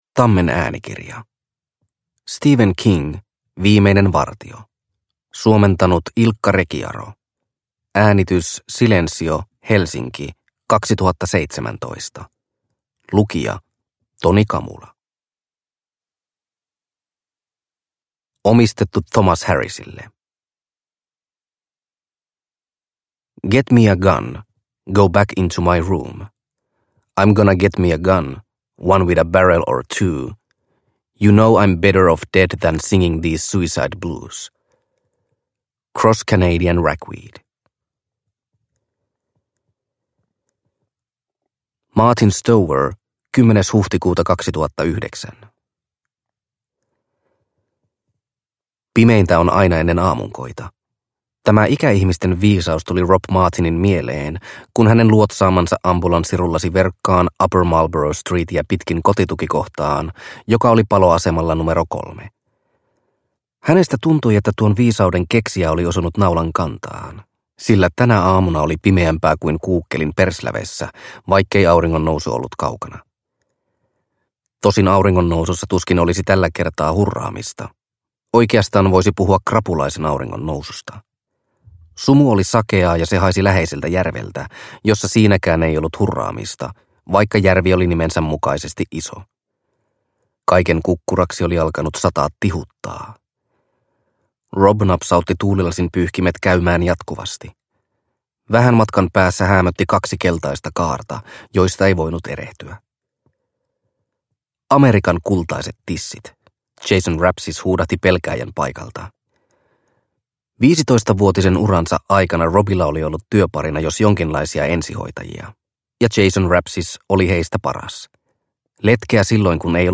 Viimeinen vartio – Ljudbok – Laddas ner